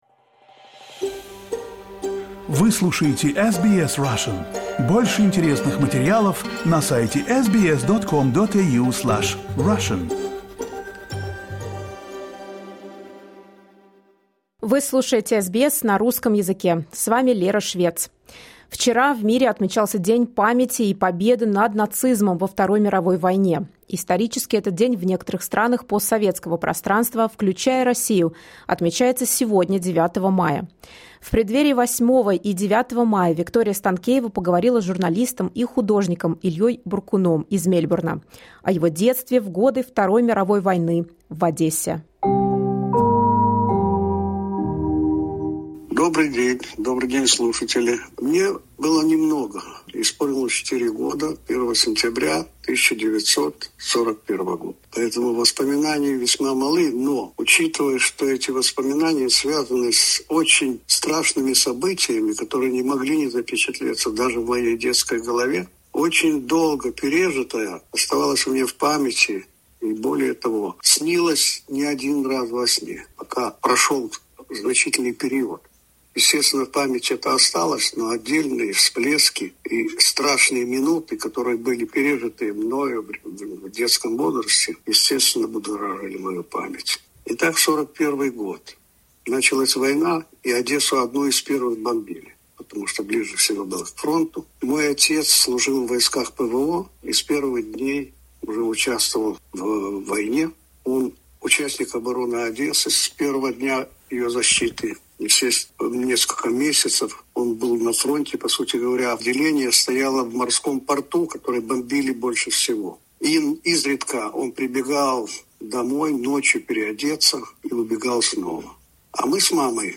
Interview with journalist